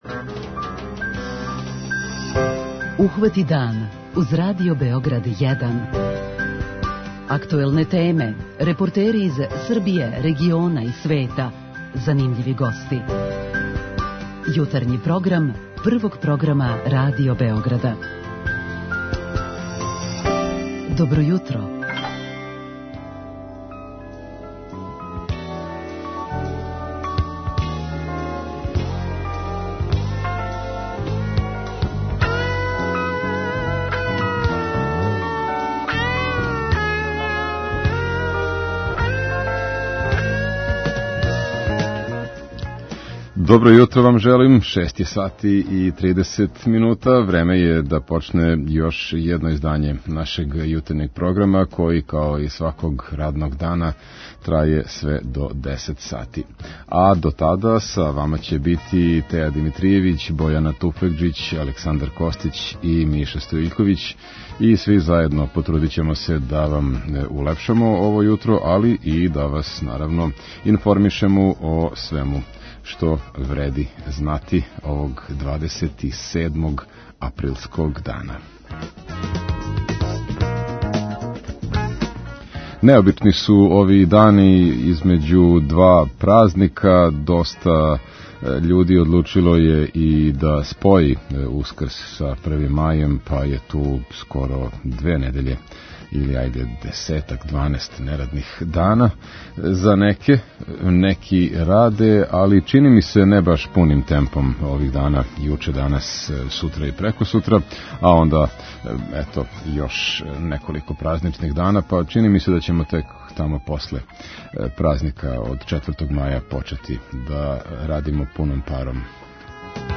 О значају и улози синдиката данас разговараћемо и са слушаоцима у редовној рубрици 'Питање јутра'.